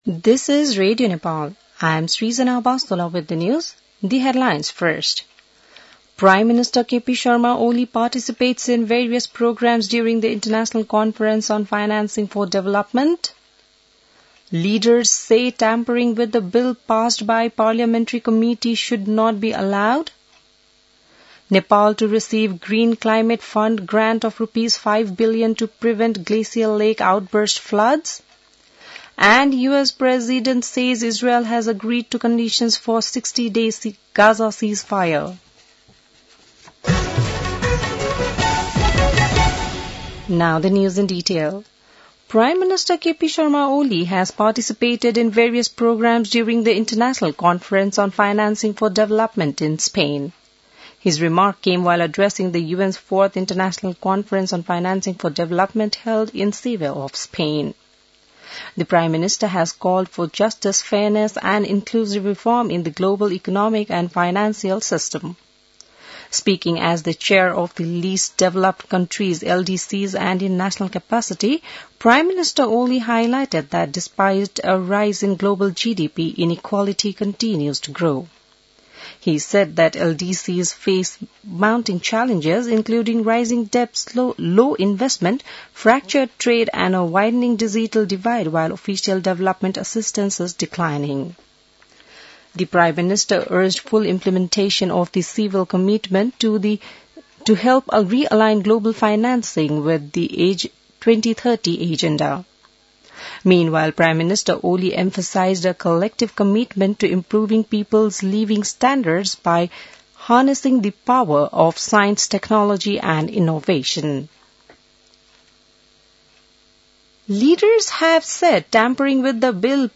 बिहान ८ बजेको अङ्ग्रेजी समाचार : १८ असार , २०८२